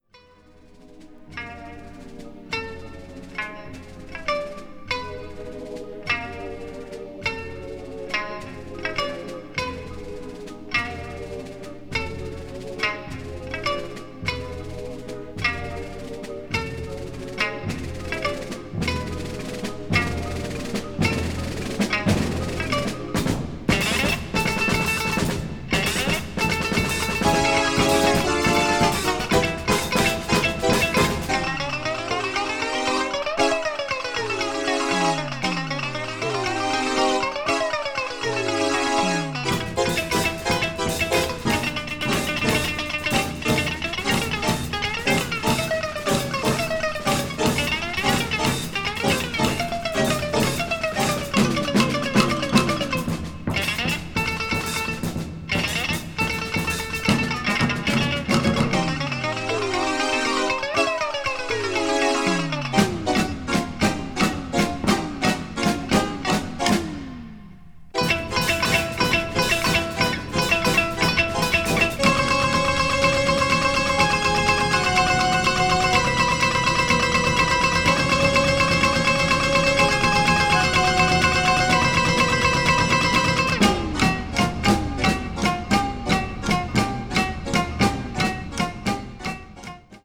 三絃